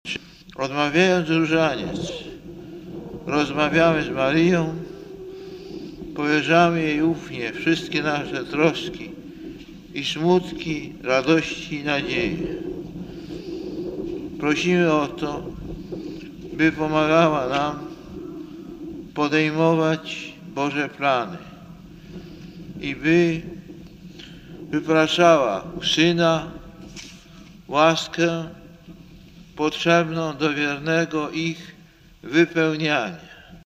Głos Papieża: (